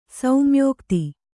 ♪ saumyōkti